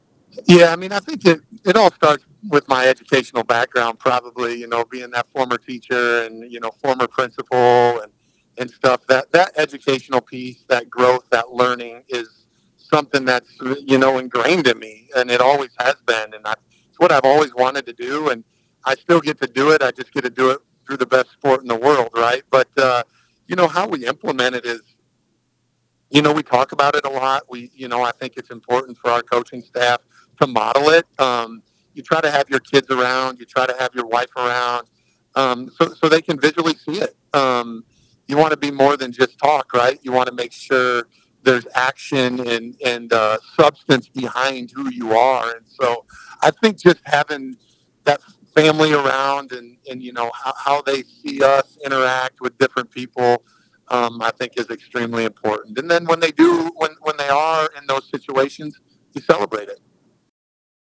During our interview he repeatedly talks about other people.